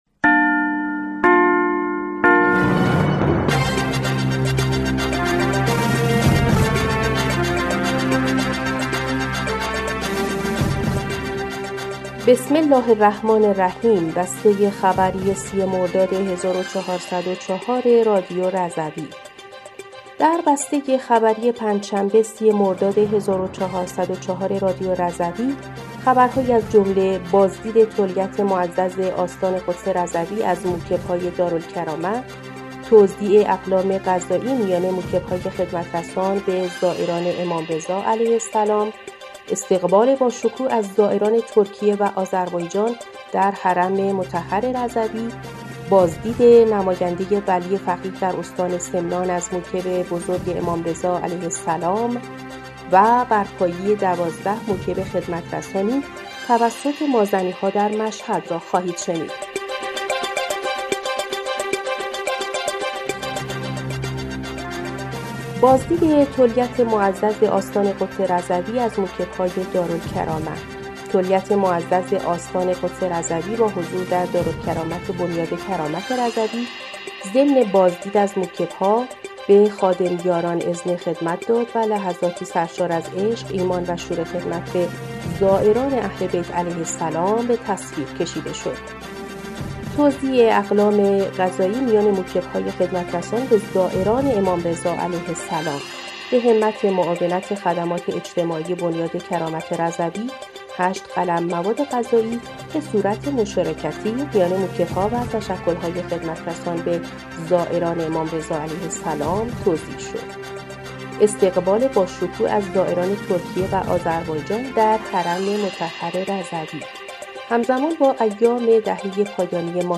بسته خبری ۳۰ مرداد ۱۴۰۴ رادیو رضوی/